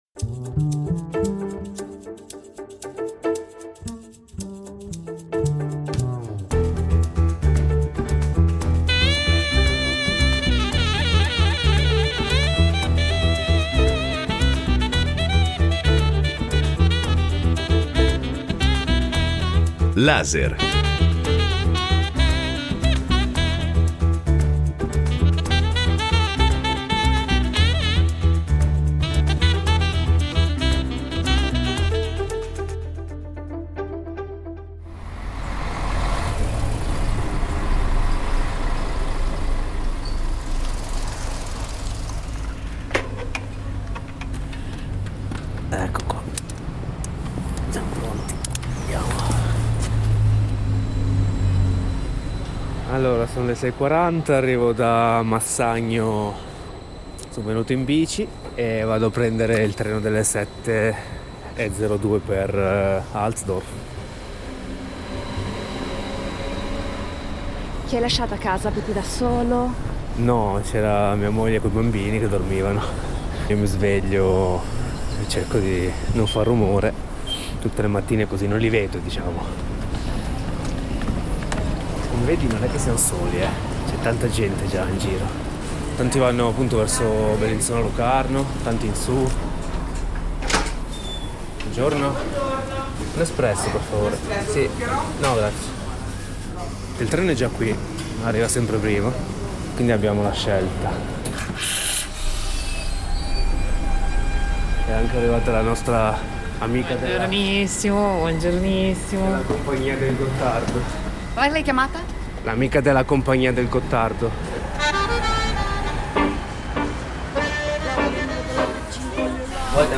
El Doraltdorf è un reportage su rotaia – non un on the road , bensì un on the rail ! – per conoscere le storie di chi ad Altdorf ha trovato salari più alti, una migliore cultura del lavoro e, sì, anche la felicità.
Con la partecipazione del sindaco di Altdorf Sebastian Züst .